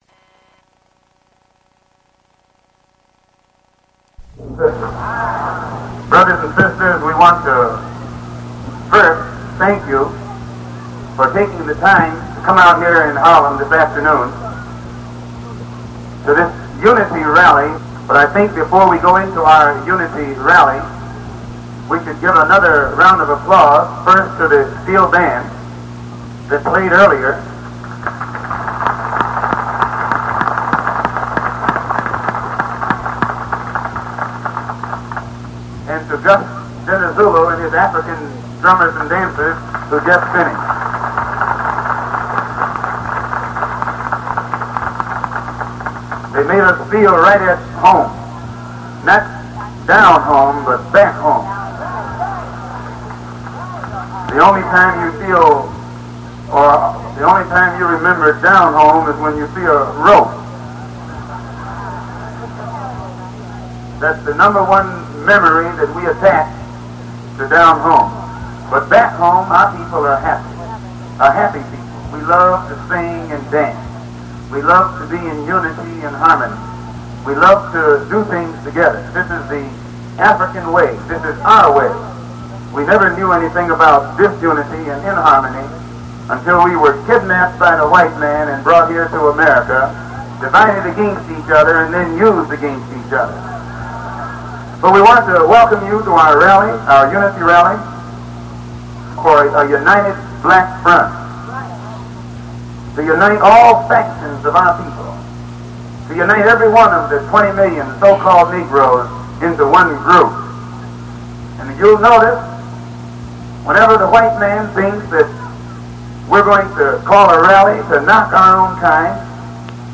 Recording of speech delivered by Malcolm X in Harlem, 1963